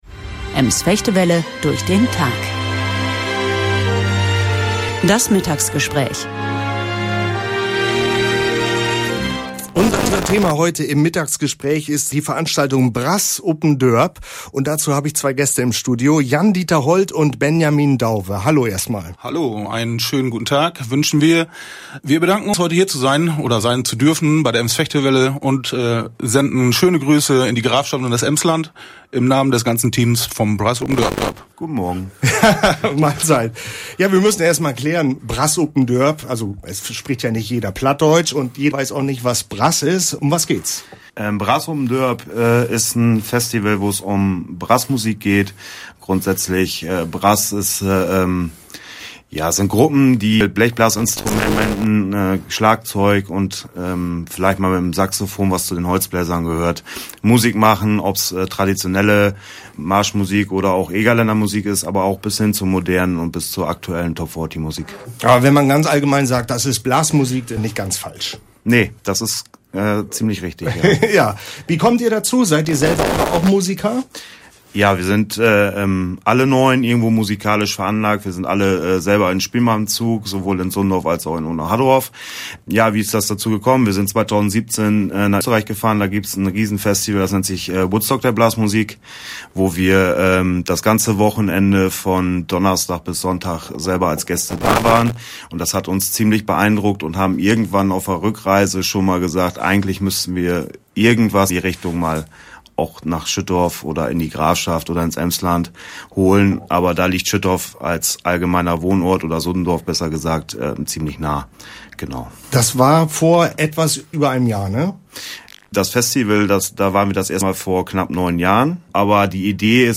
Talk: 1.
Download Podcast Leider hat unser Mitschnitt-System aktuell eine Störung. Die Tonprobleme in der Aufzeichnung bitten wir zu entschuldigen.
Musikunterlegungen und Musikstücke dürfen wir aus lizenzrechtlichen Gründen nicht online veröffentlichen.